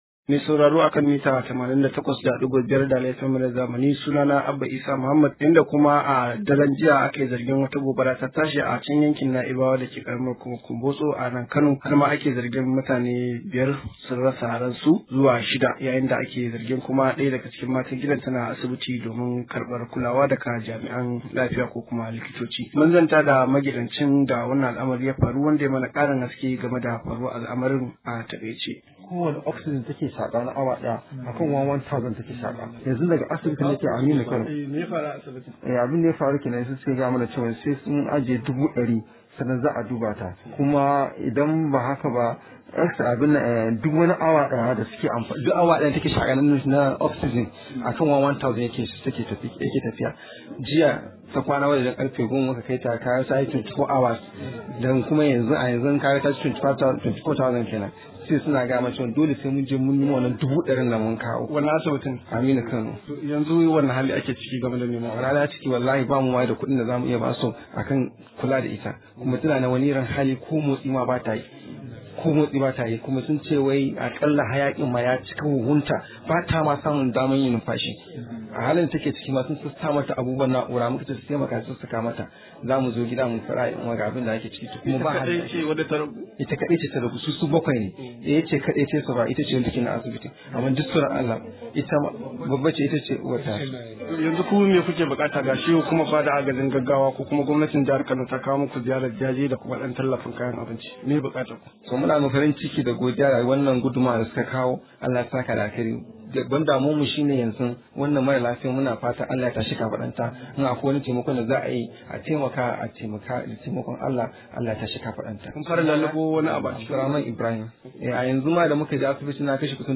Shugaban hukumar Kwamared Sale Aliyu Jili ne ya bayyana hakan a zantawar sa da gidan rediyon Dala.